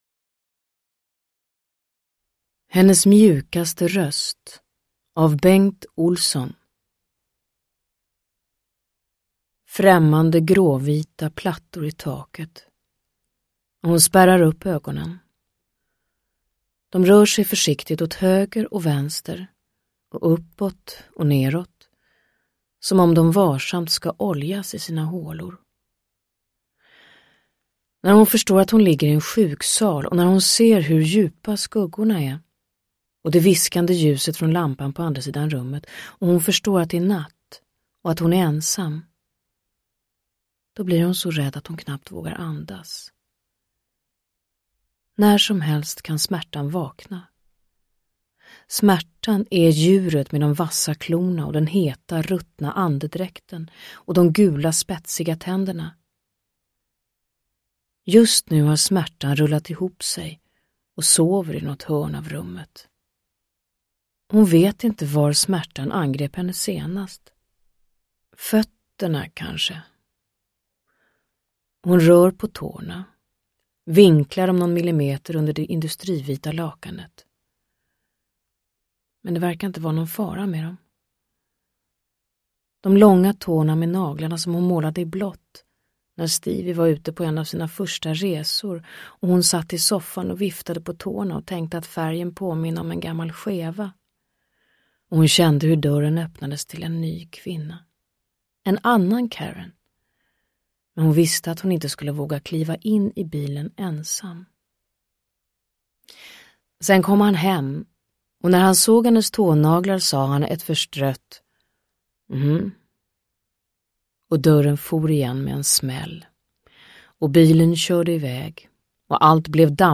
Downloadable Audiobook
Narrator
Pernilla August